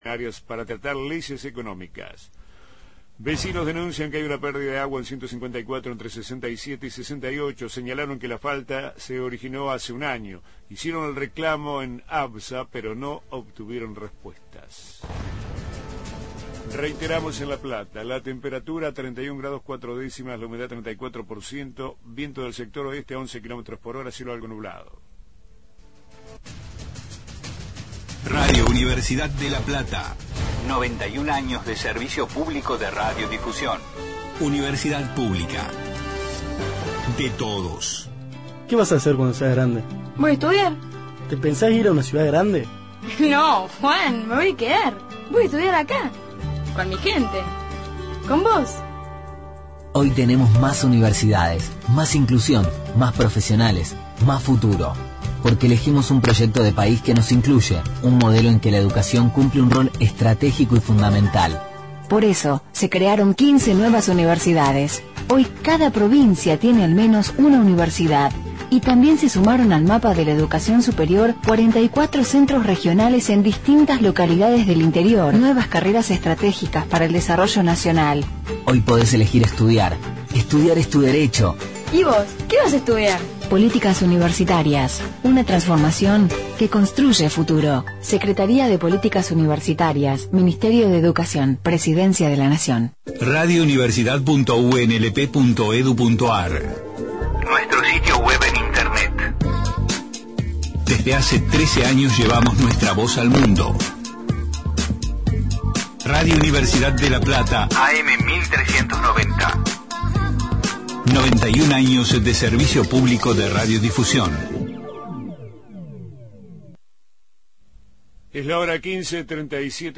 Columna de cine